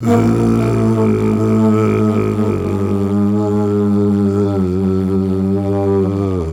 Flute 51-02.wav